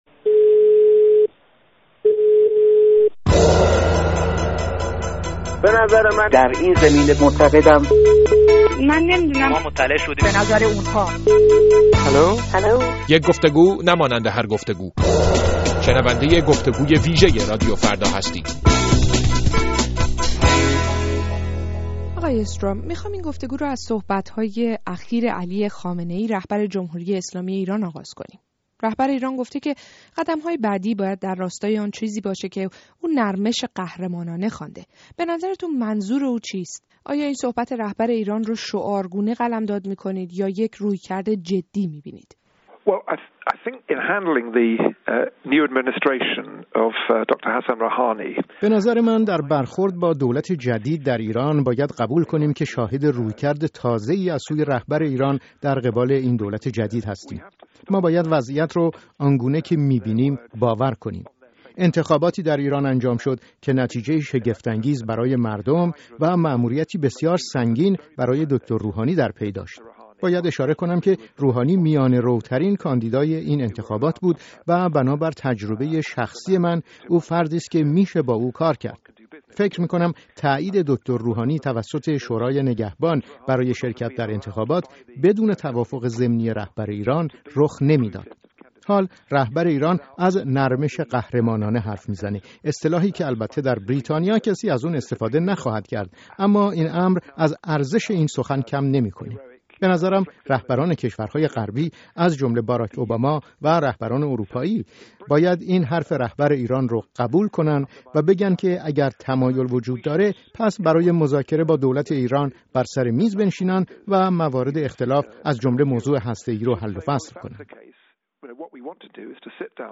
جک استراو، وزیر پیشین خارجه بریتانیا، از تجربه همکاری با افرادی چون رييس جمهوری ايران و وزير امور خارجه اش، راه‌های پيش روی غرب و اشتباهات آمريکا در سال‌های گذشته با رادیو فردا به گفتگو نشسته اشت.